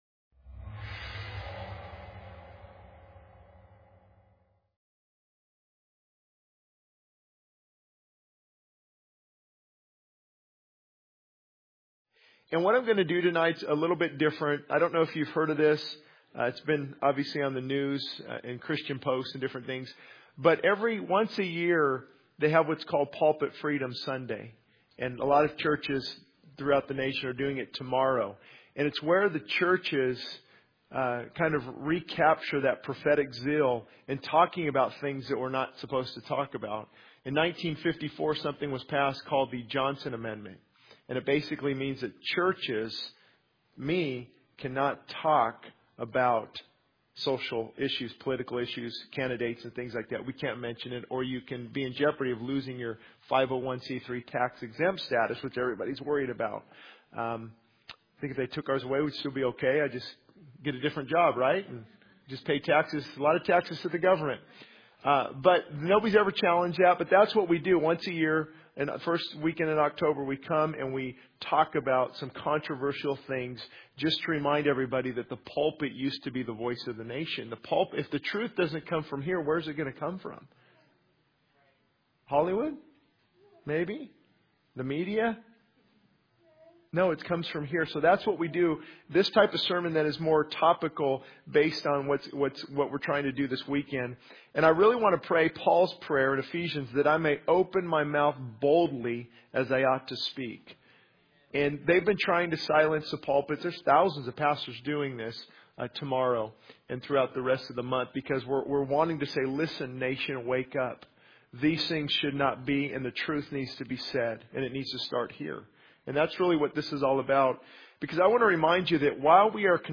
He calls for a return to God, urging believers to seek His face and turn from their wicked ways to heal the land. The sermon serves as a wake-up call for the church to be bold in proclaiming truth and to take responsibility for the spiritual cond